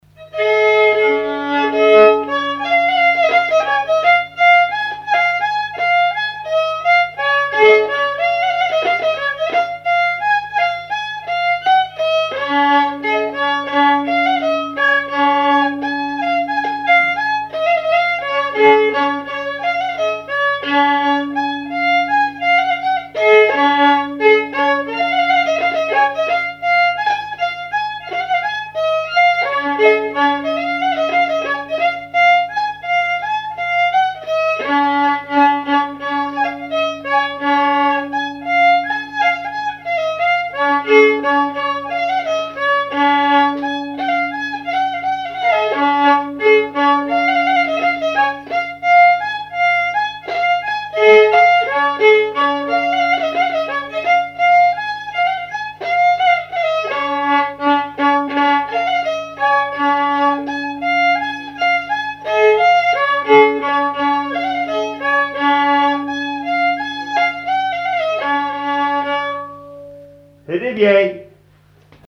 Mémoires et Patrimoines vivants - RaddO est une base de données d'archives iconographiques et sonores.
danse : scottich trois pas
répertoire d'air pour la danse au violon et à l'accordéon
Pièce musicale inédite